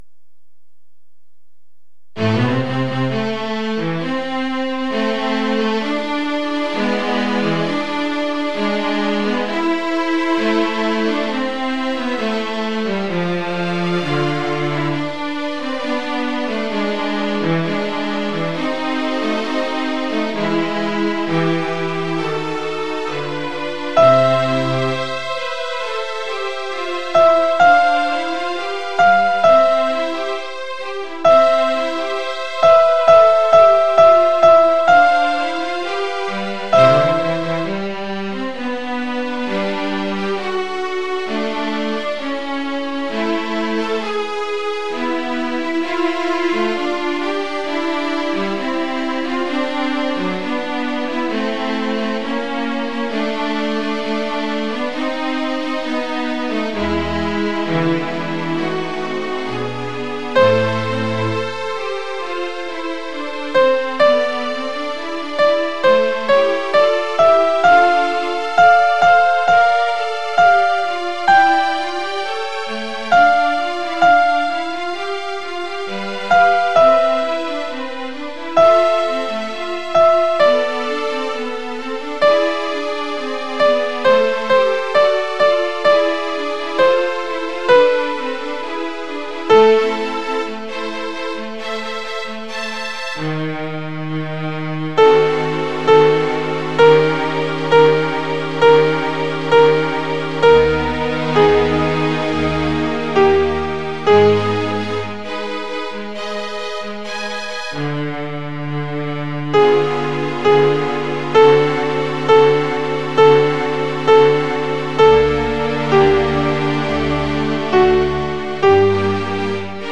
Les Choeurs de Paris 13
confutatis_sop.mp3